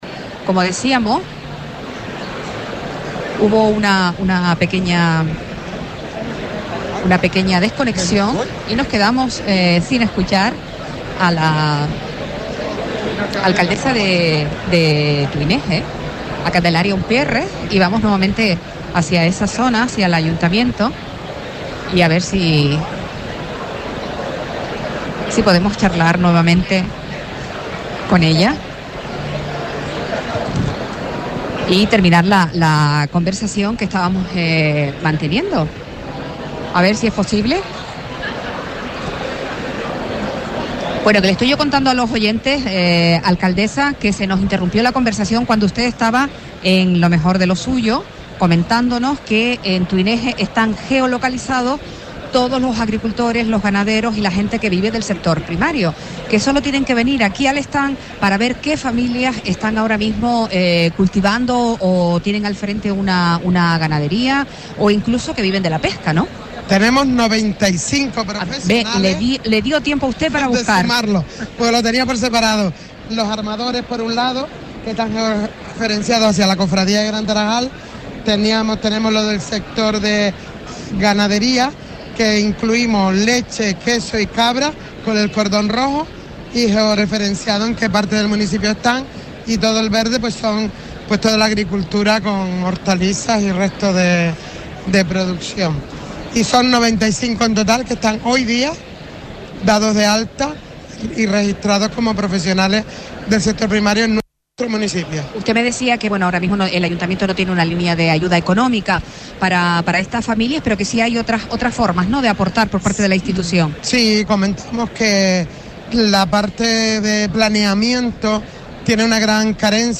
Entrevistas
Tuineje geolocaliza en su stand, en Feaga, los diferentes productores y familias dedicadas al sector primario, nos lo ha contado su alcaldesa, Candelaria Umpiérrez.